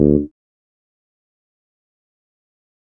D THUMB.wav